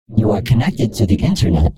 Звук синтетического голоса: Internet
Синтезированный голос: Internet